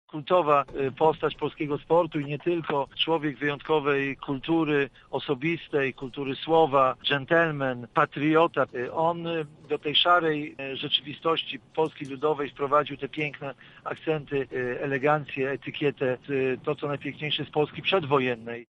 Zmarłego dziennikarza wspomina wybitny polski tenisista Wojciech Fibak: